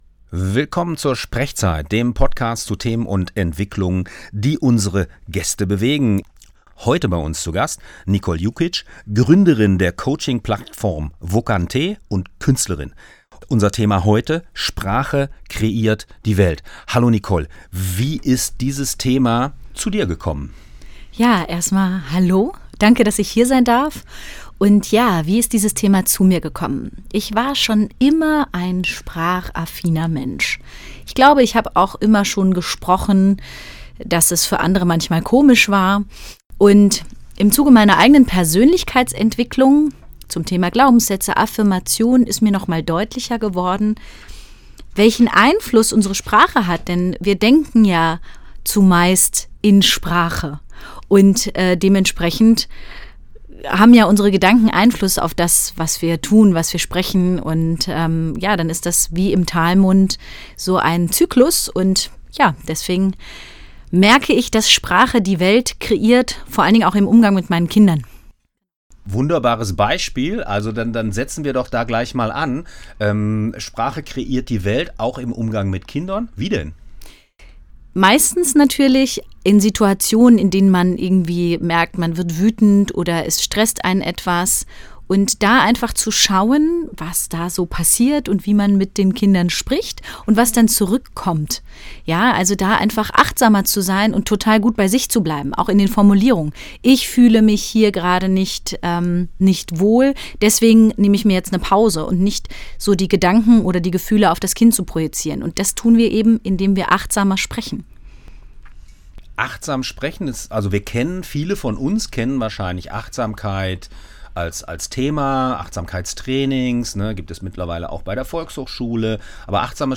Dieser SprechZeit-Podcast wurde am 2.12.2024 beim Freien Radio Kassel aufgezeichnet.
Interview